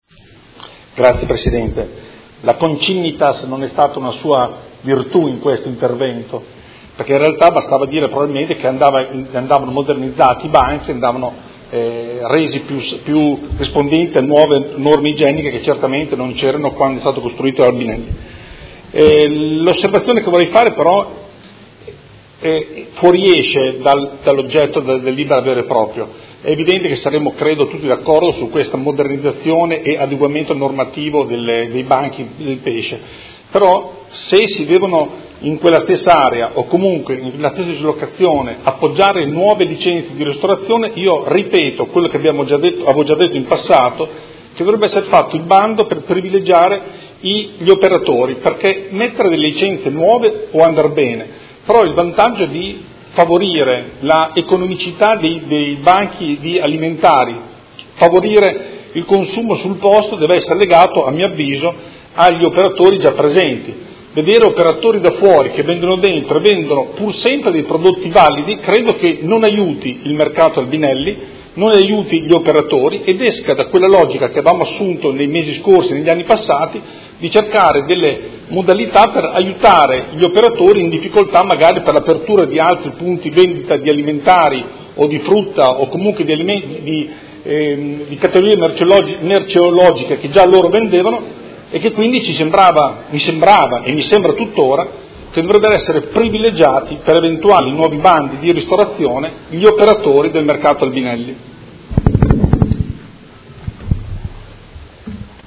Seduta del 28/03/2019. Dibattito su proposta di deliberazione: Regolamento comunale del Mercato quotidiano di generi alimentari denominato “Mercato Albinelli” – Approvazione